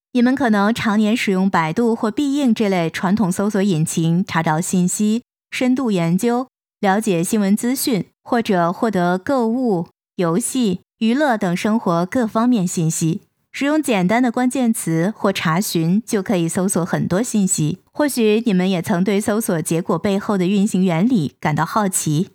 Chinese_Female_045VoiceArtist_2Hours_High_Quality_Voice_Dataset
Text-to-Speech